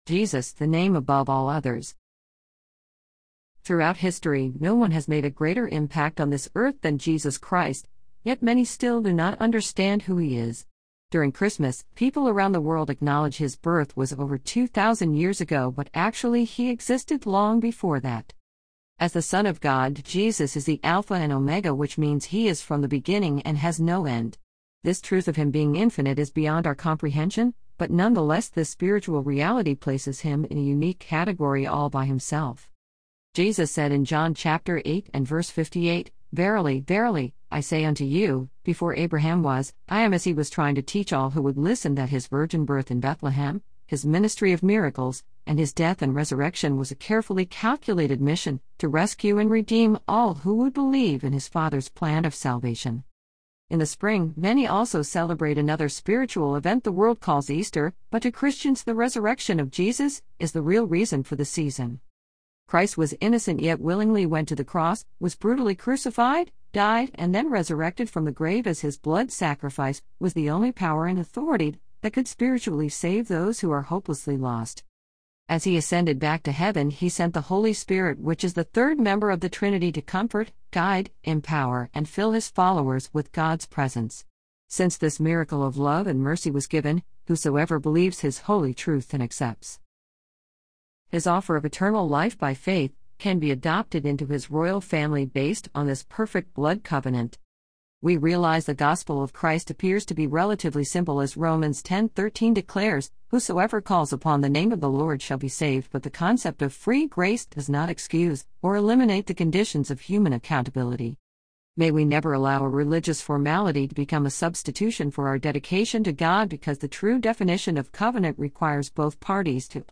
An audio recording of a message from the “Alabamian” newspaper in Jackson Alabama.